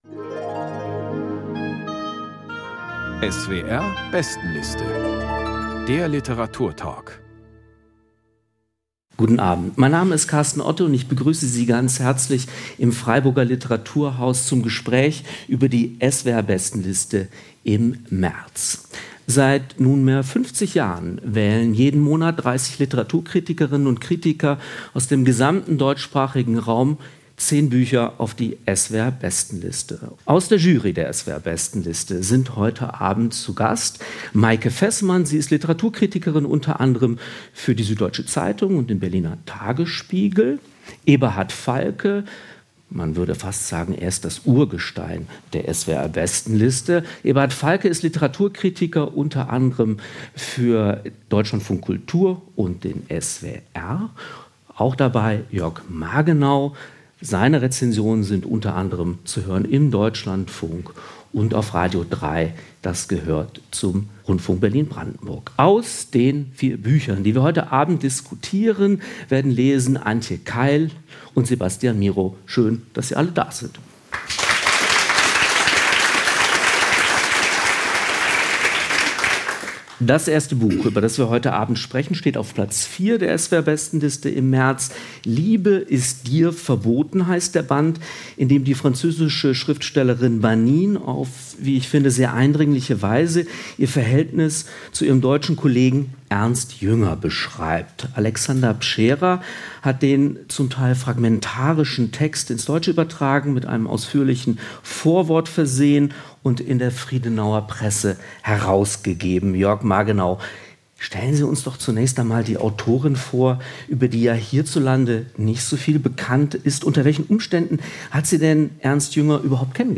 Banine: Liebe ist dir verboten. Ernst Jünger und ich | Lesung und Diskussion ~ SWR Kultur lesenswert - Literatur Podcast